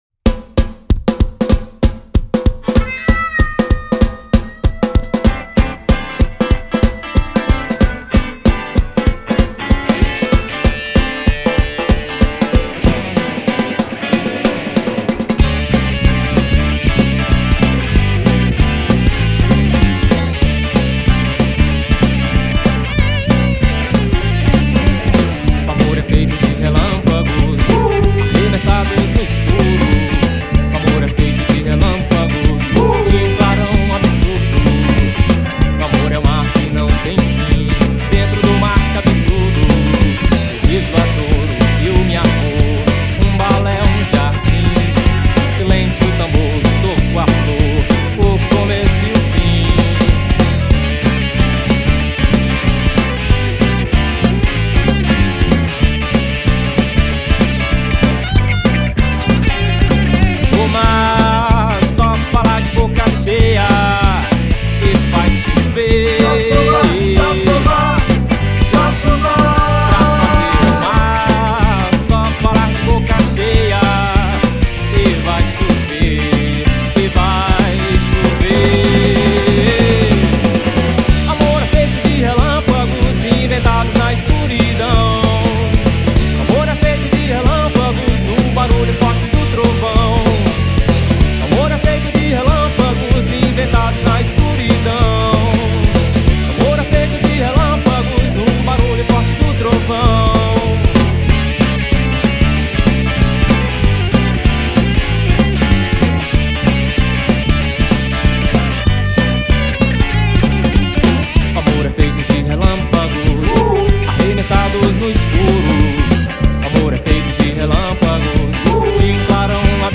265   04:53:00   Faixa:     Rock Nacional
Gaita
Cavaquinho, Pandeiro
Guitarra
Baixo Elétrico 6
Bateria